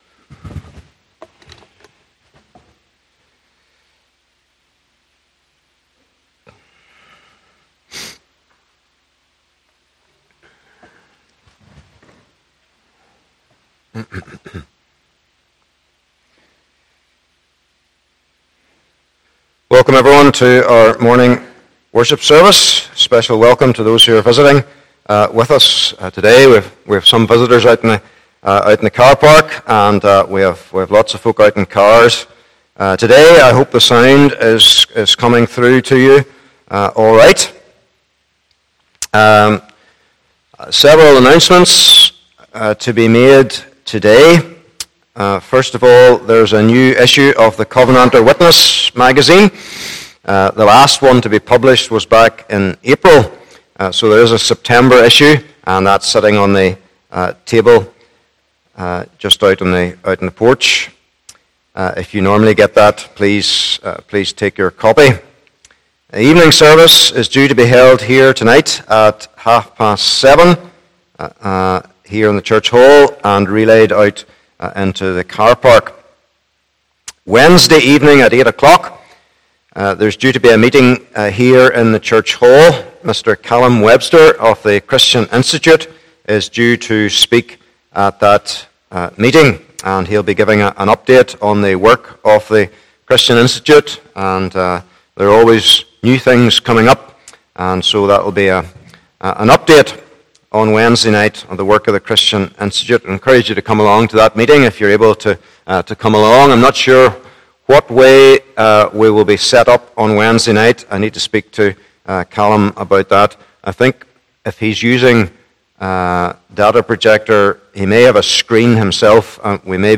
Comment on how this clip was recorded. Passage: Philippians 3 : 1 - 8 Service Type: Morning Service